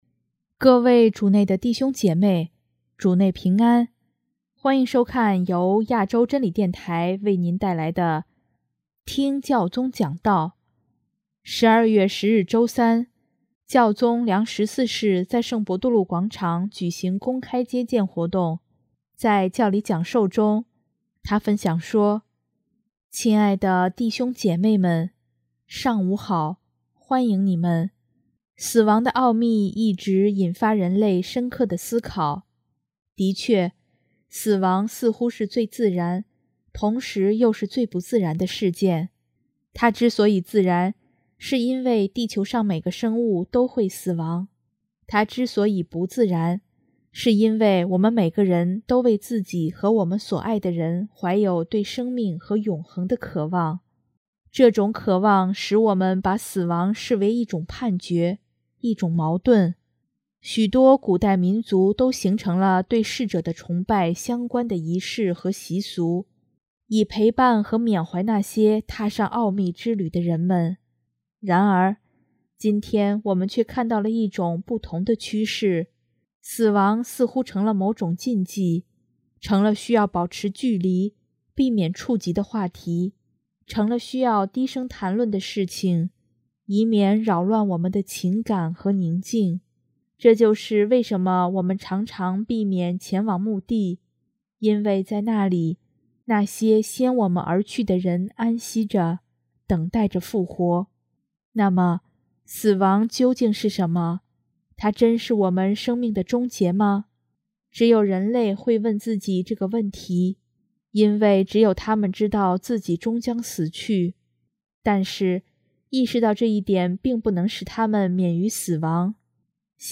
【听教宗讲道】|没有死亡的人生一定是幸福的人生吗？
12月10日周三，教宗良十四世在圣伯多禄广场举行公开接见活动。